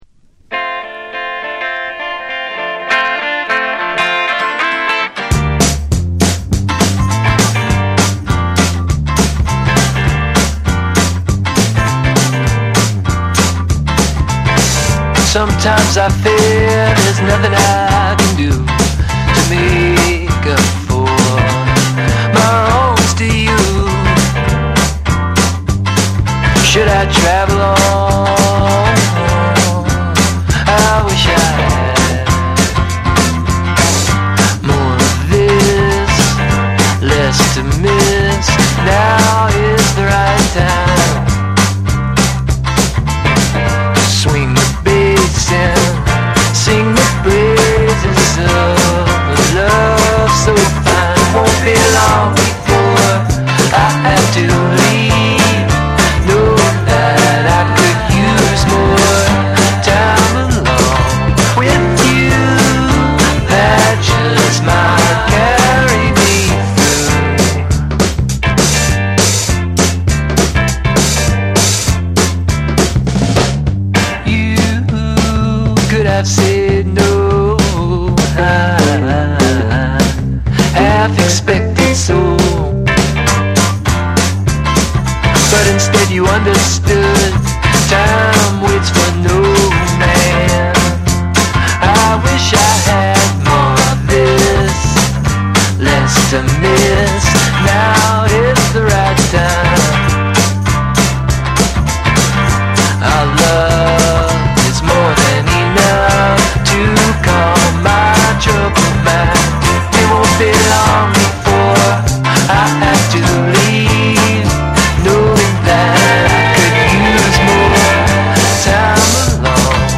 USフォーク・ロック・バンド
オーガニックでメロウなフォークサウンドに加え、モダンな解釈も楽しめる一枚。
NEW WAVE & ROCK / BREAKBEATS